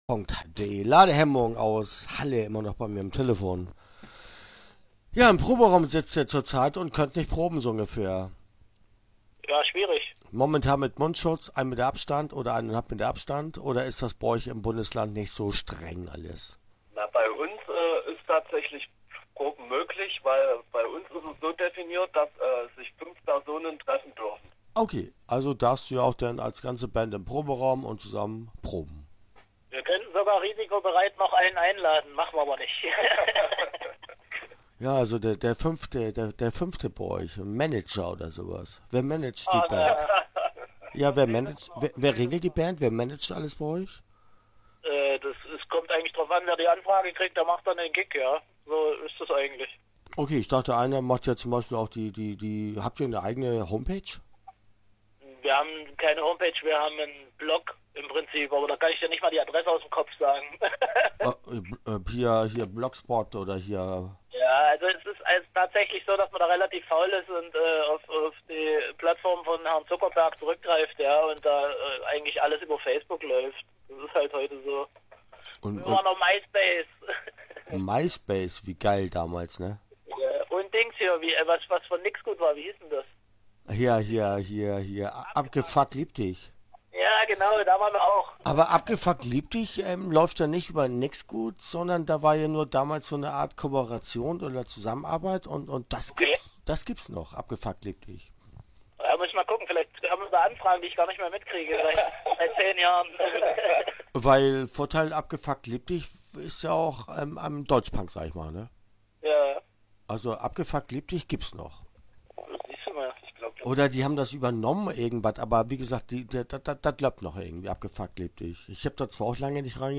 Ladehemmung - Interview Teil 1 (10:24)